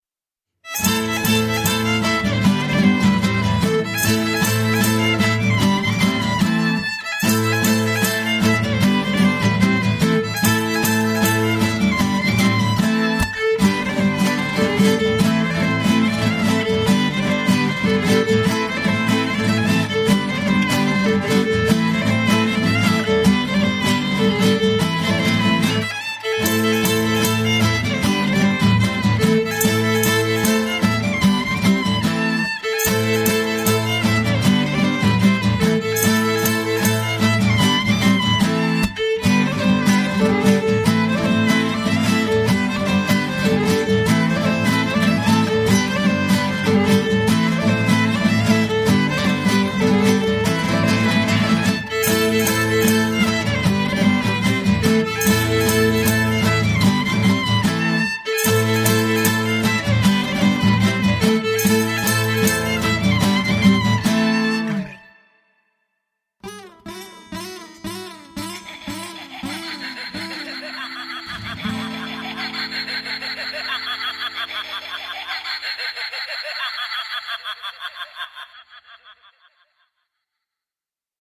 Žánr: World music/Ethno/Folk
housle
kytara